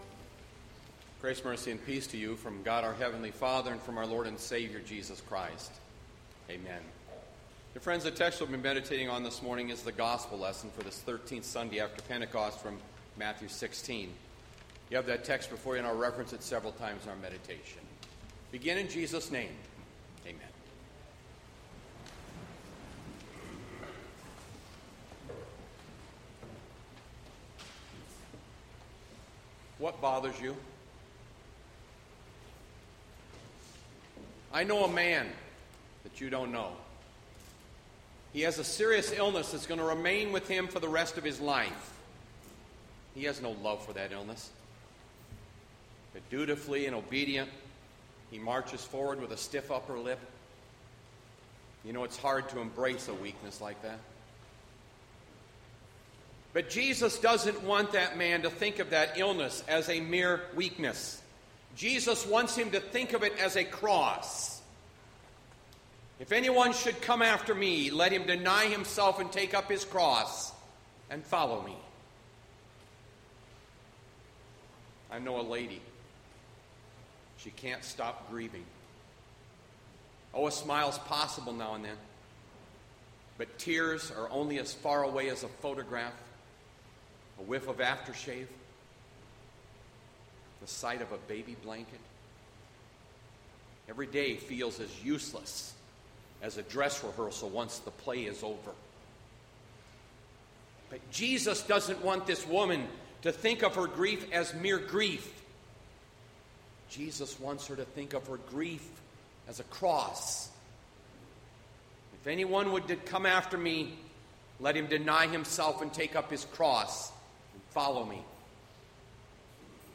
Aug 30, 2020  SERMON ARCHIVE